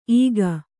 ♪ īga